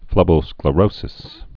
(flĕbō-sklə-rōsĭs)